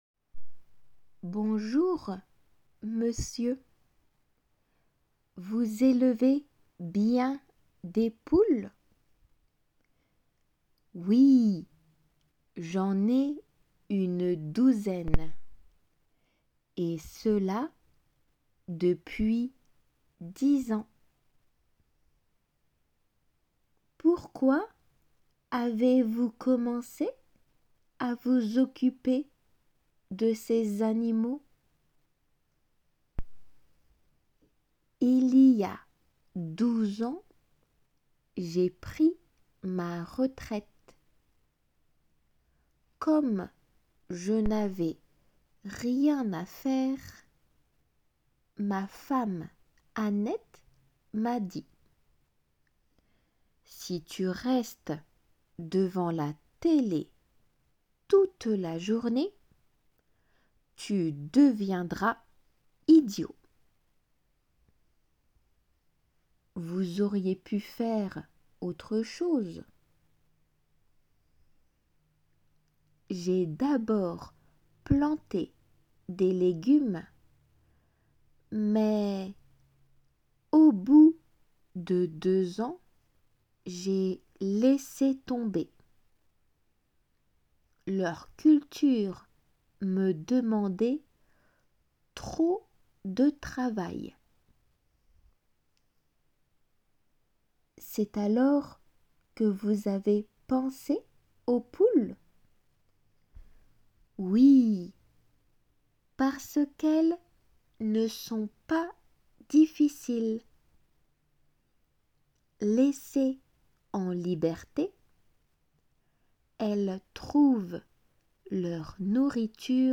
読まれるテキスト
聞きとり用の音声は　5セット　会話体で構成されている傾向。又、質問→→応答の答えで構成。